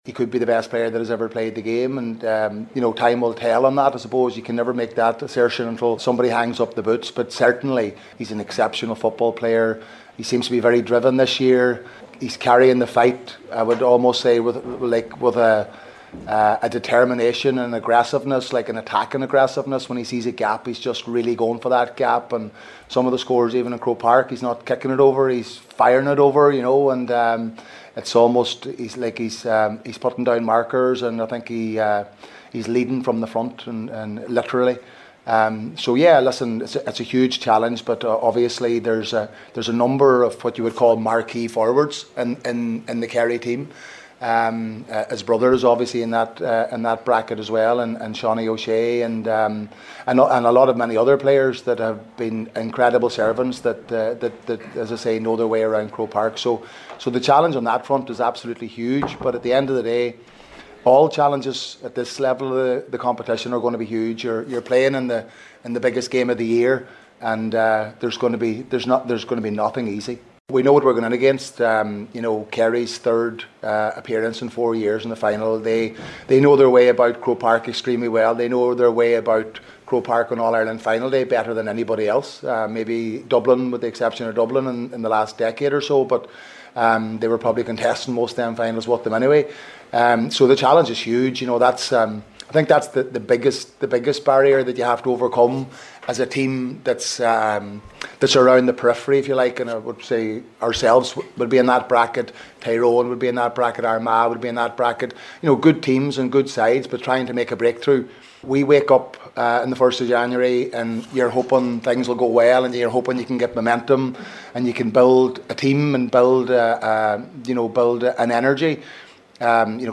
The Donegal boss spoke about the challenge Kerry will bring at a press event in Convoy last night and said David Clifford might be the best player in the history of the sport…